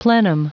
Prononciation du mot plenum en anglais (fichier audio)
Prononciation du mot : plenum